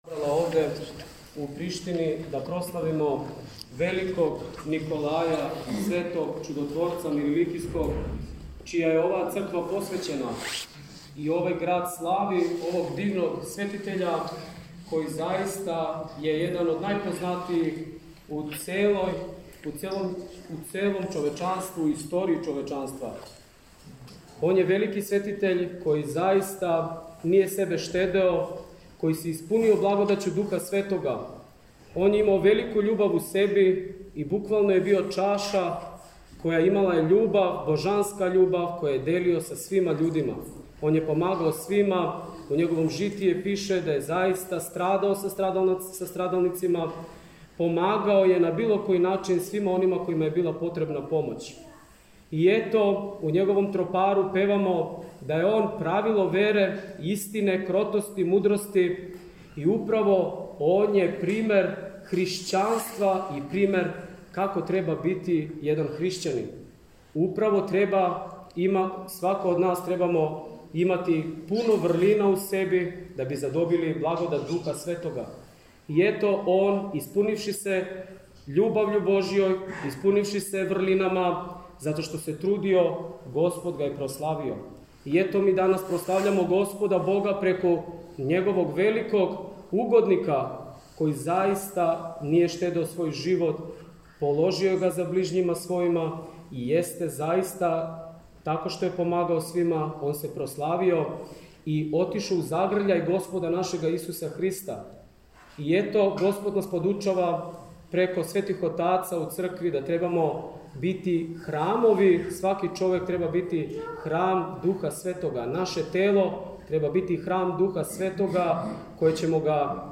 Литургијски прослављен Никољдан у Приштини